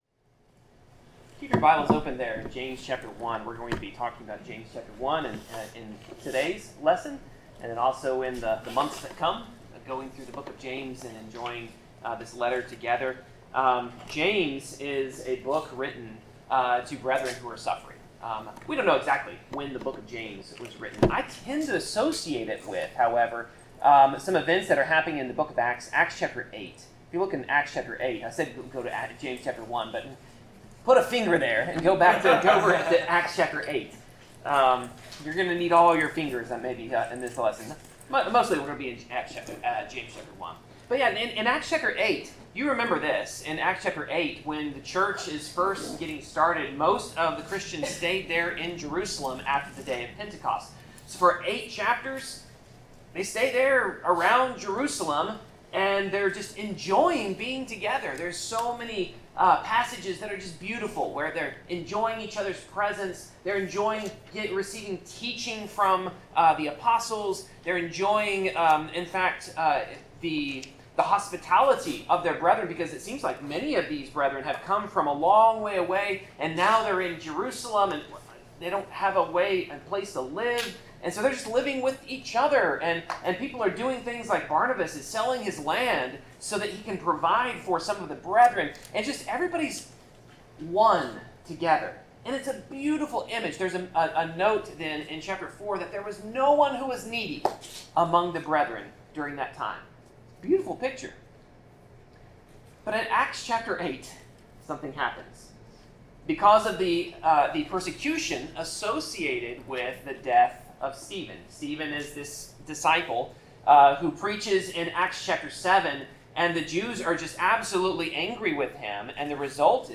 Passage: James 1:1-12 Service Type: Sermon